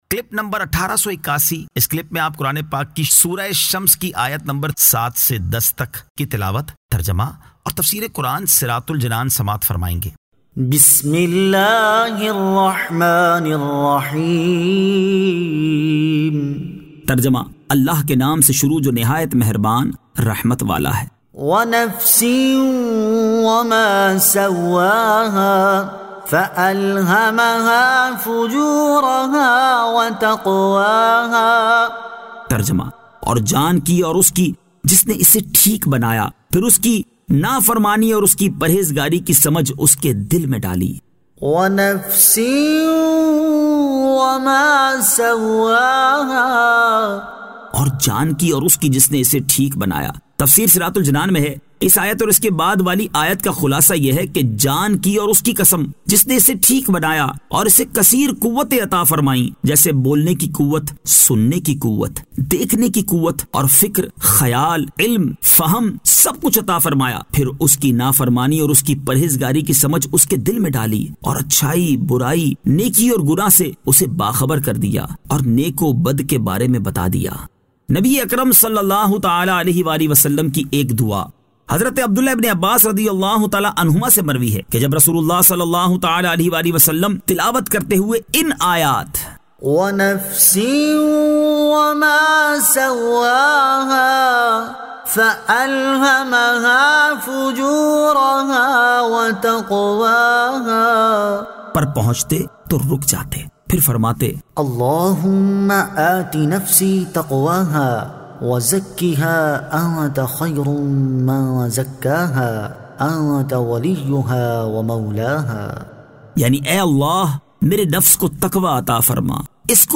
Surah Ash-Shams 07 To 10 Tilawat , Tarjama , Tafseer